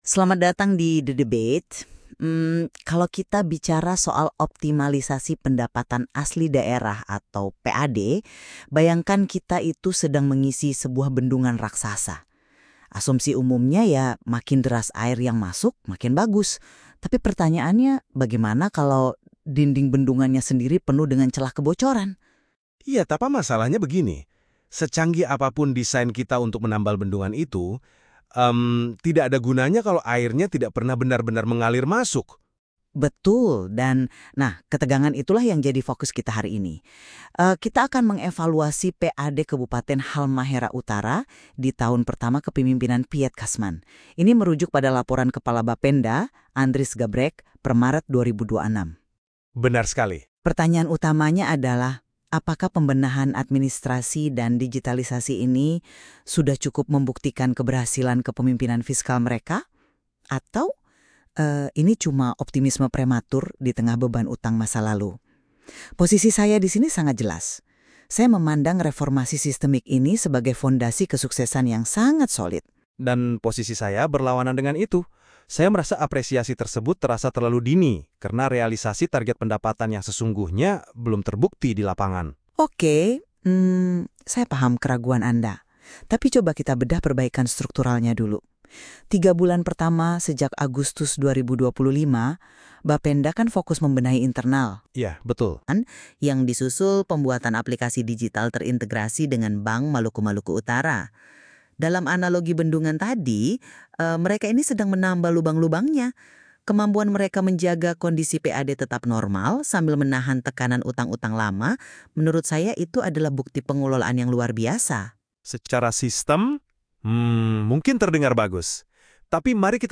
Audio podcast ini diproduksi menggunakan aplikasi NotebookLM by Google